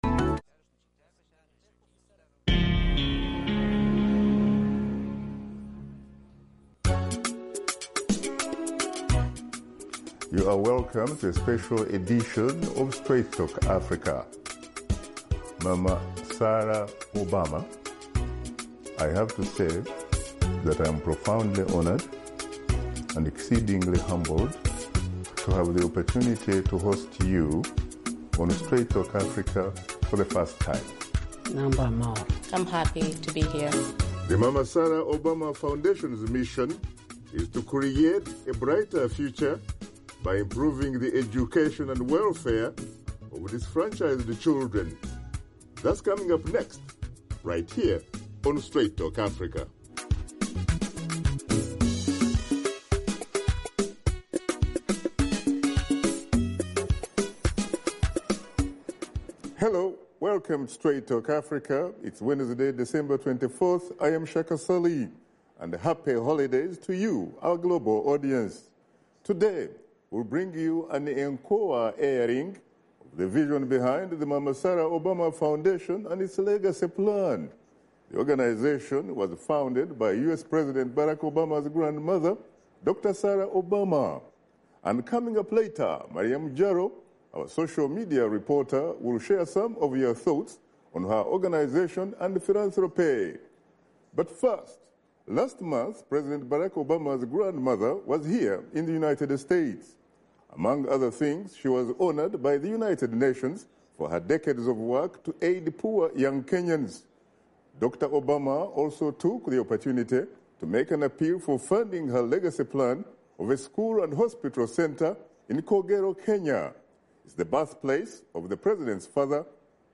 Pre-Taped Interview
Washington Studio Guests